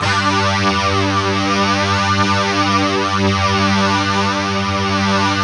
Index of /90_sSampleCDs/Optical Media International - Sonic Images Library/SI1_DistortGuitr/SI1_400 GTR`s